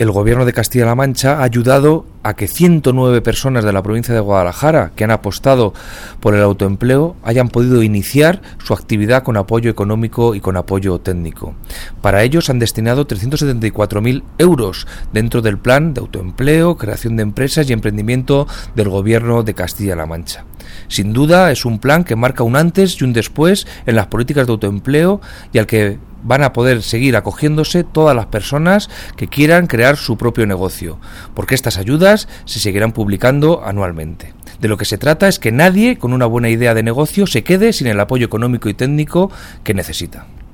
El delegado de la Junta en Guadalajara habla de las subvenciones al emprendimiento otorgadas por el Gobierno regional en la provincia de Guadalajara